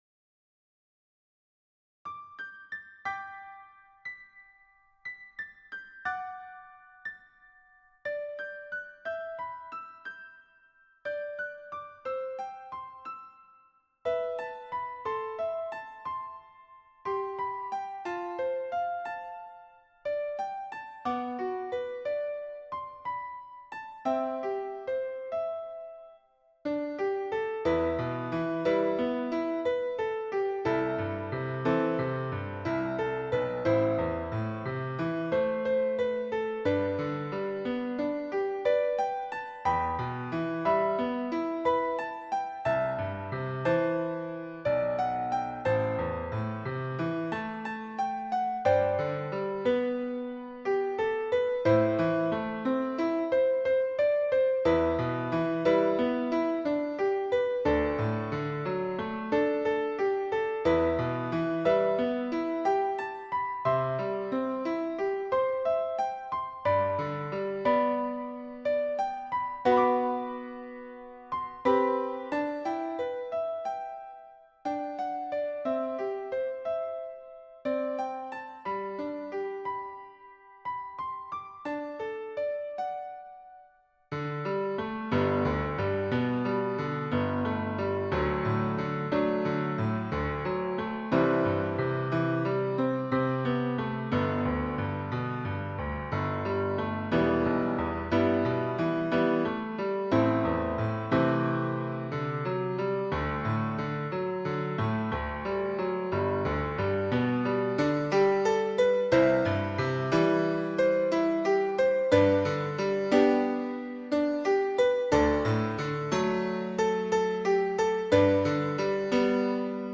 Voicing/Instrumentation: Piano Prelude/Postlude , Piano Solo